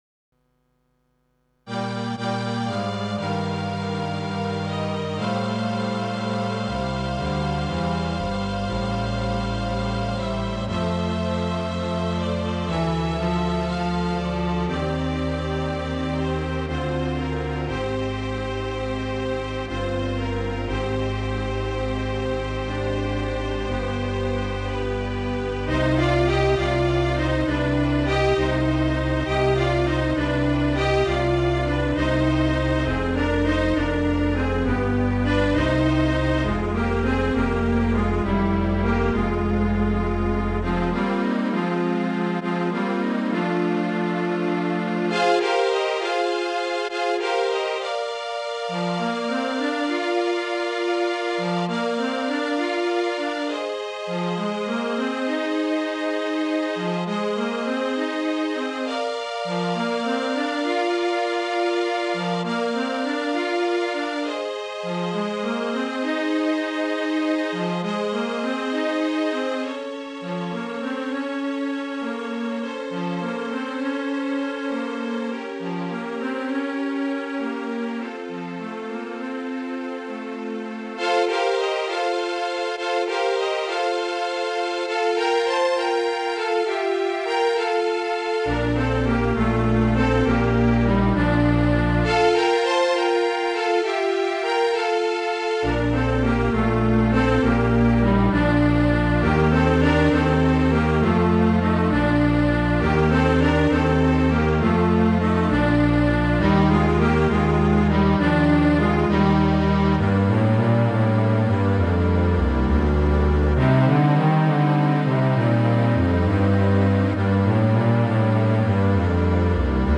Strings only version without woodwind.